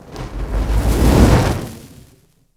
FireExplosion2.wav